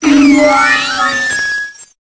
Cri de Togekiss dans Pokémon Épée et Bouclier.